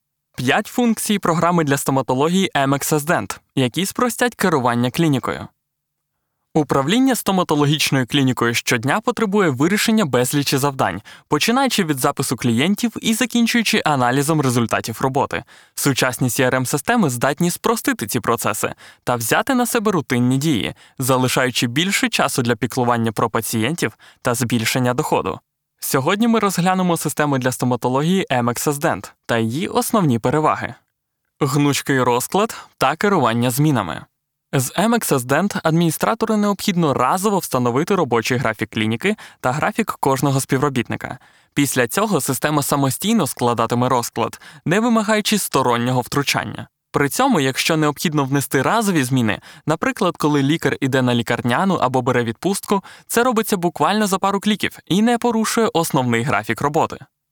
Comercial, Natural, Versátil, Amable, Travieso
Explicador
Known for his reliable, friendly, conversational and playful voice.